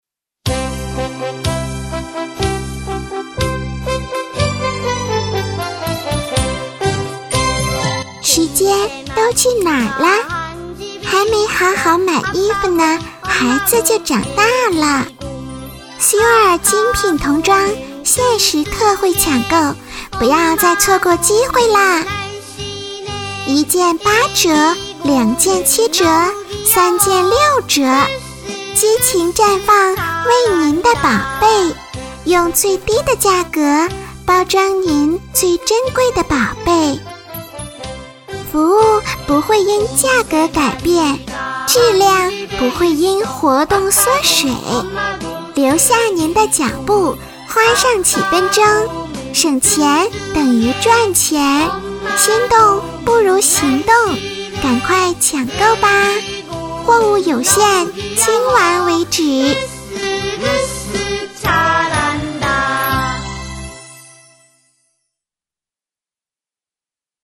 女93-suer精品童装-女童
女93专题广告 v93
女93-suer精品童装-女童.mp3